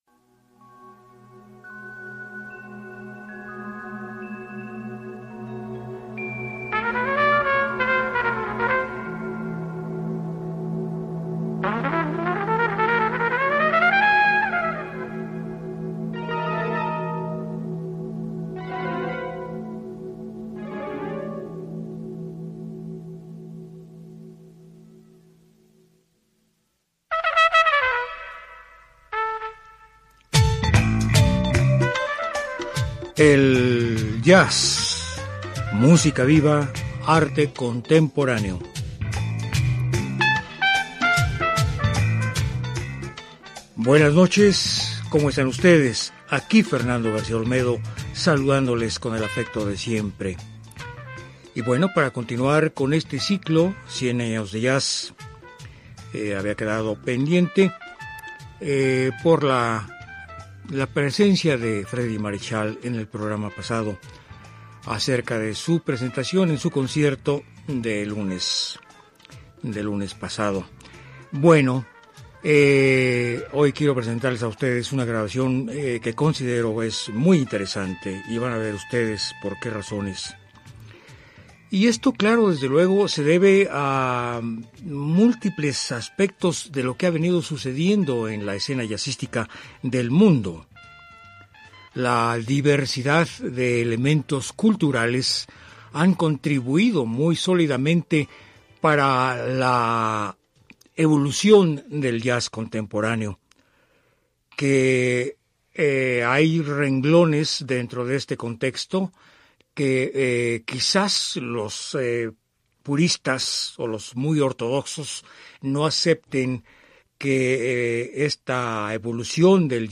flautista y saxofonista estadounidense de jazz.